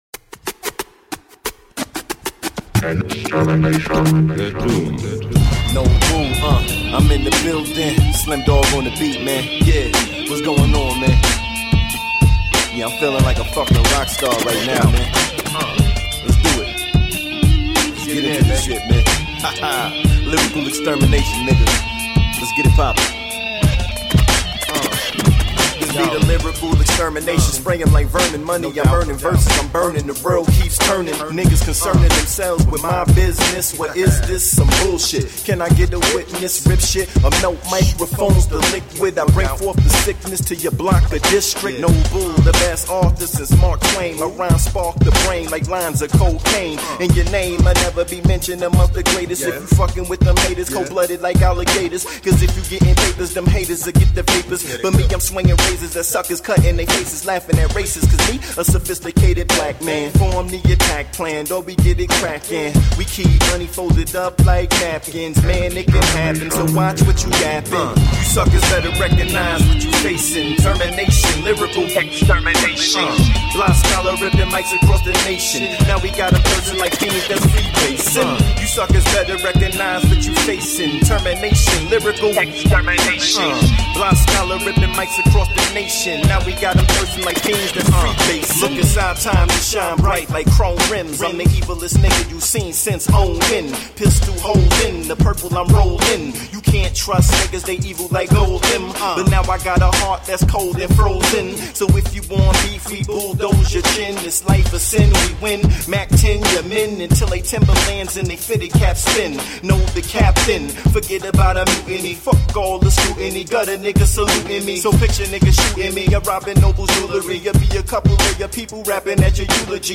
Award winning independent hip hop group.
Tagged as: Hip Hop, Funk